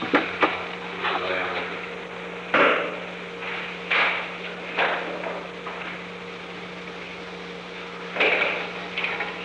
Secret White House Tapes
Conversation No. 635-2
Location: Oval Office
[Unintelligible]